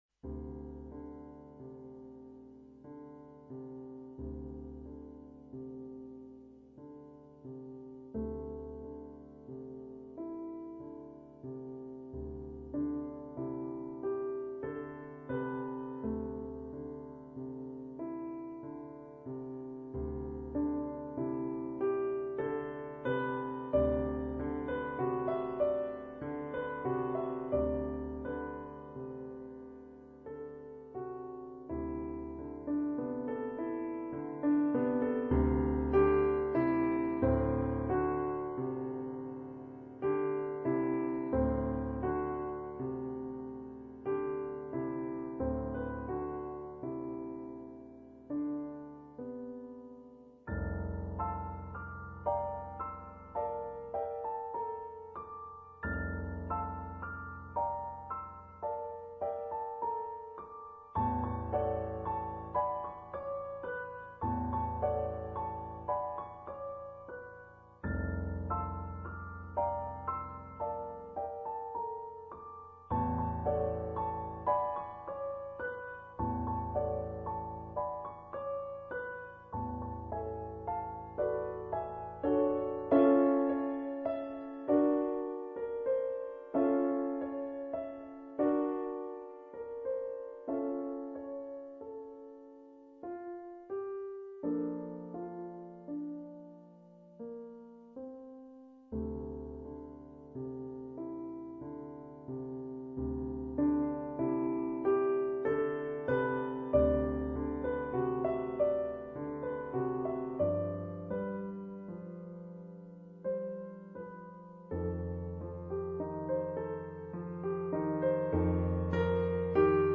Instrument(s): piano solo